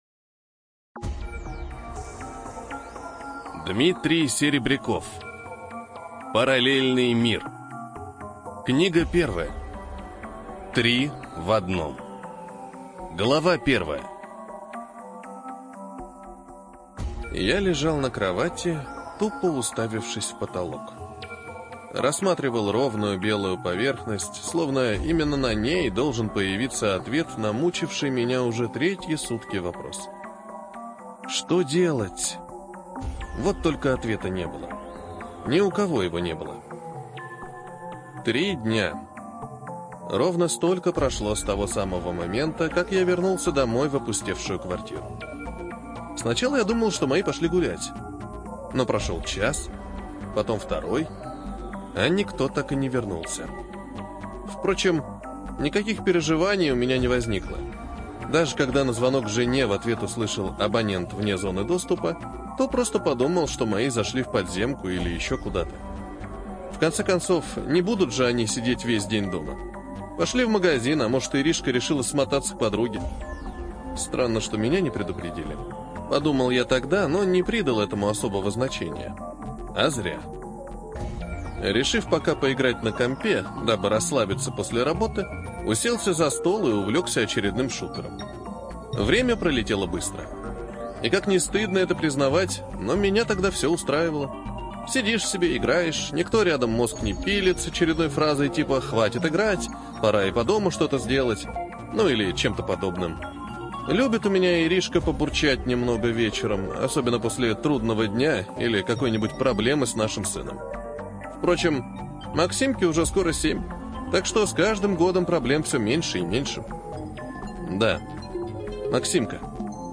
Внимание! Аудиозапись содержит нецензурную брань.